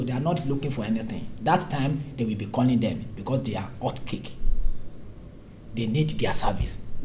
S1 = Bruneian female S3 = Nigerian male
Intended Words: hot cake Heard as: (out kick) Discussion: There is no initial [h] in hot .